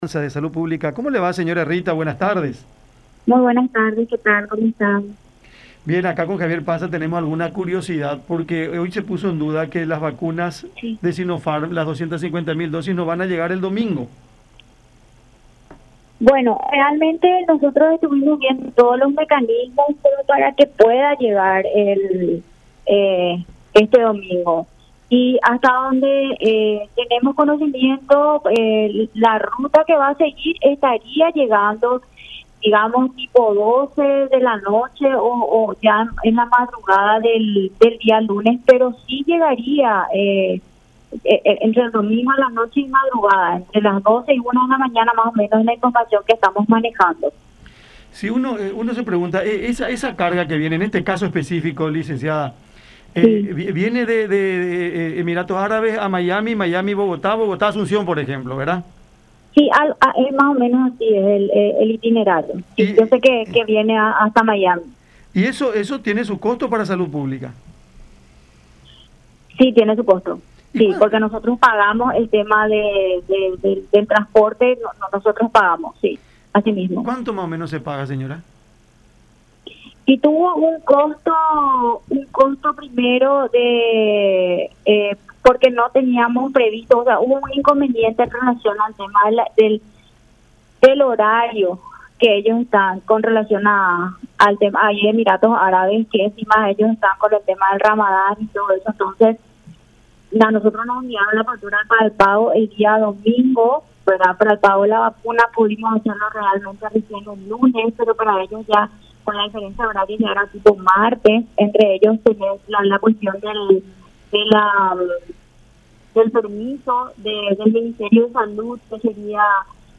en conversación con Cada Siesta por La Unión